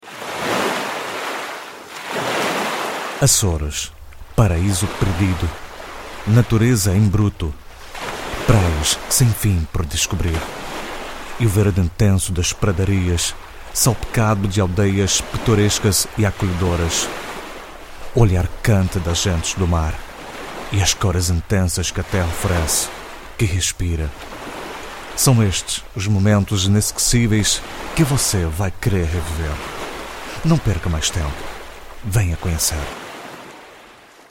Sprechprobe: Industrie (Muttersprache):
Portuguese voice over artist.